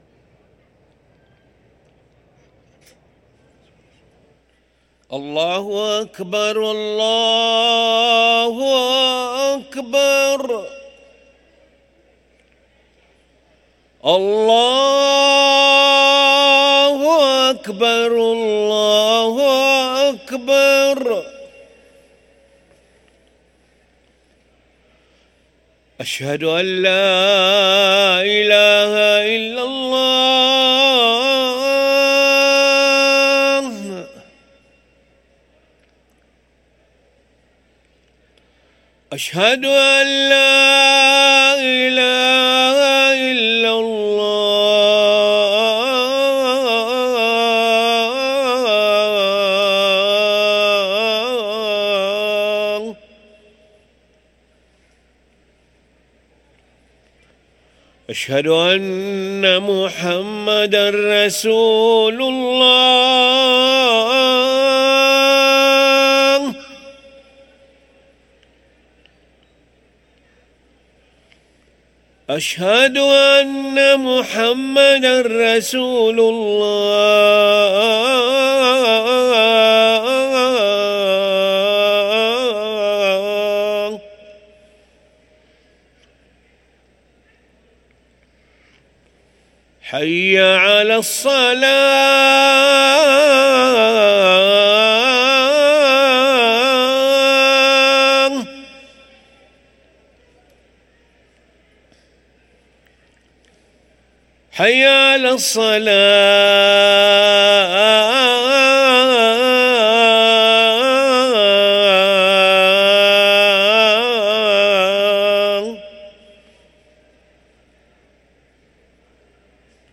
أذان العشاء للمؤذن علي ملا الأحد 28 ربيع الآخر 1445هـ > ١٤٤٥ 🕋 > ركن الأذان 🕋 > المزيد - تلاوات الحرمين